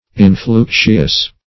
Influxious \In*flux"ious\, a.